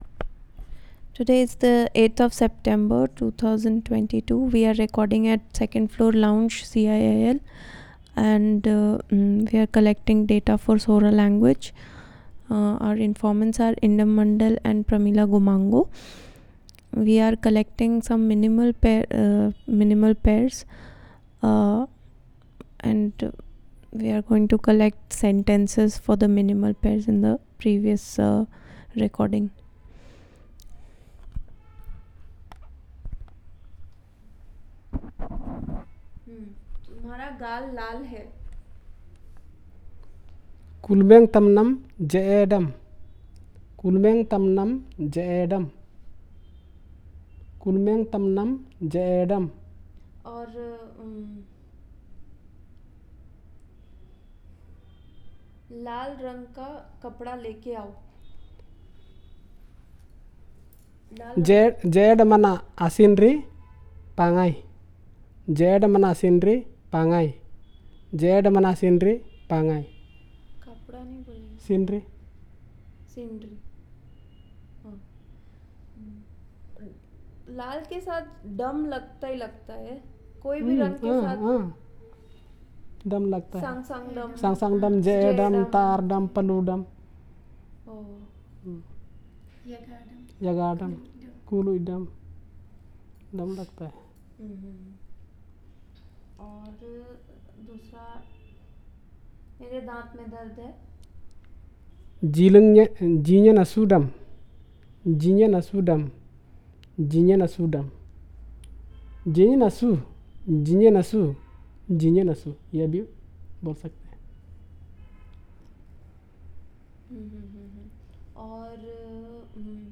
Elicitation of minimal pairs